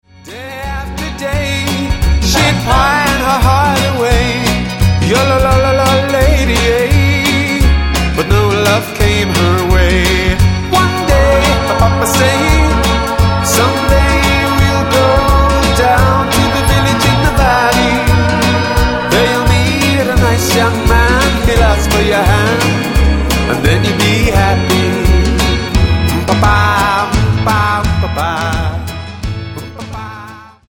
--> MP3 Demo abspielen...
Tonart:F# Multifile (kein Sofortdownload.
Die besten Playbacks Instrumentals und Karaoke Versionen .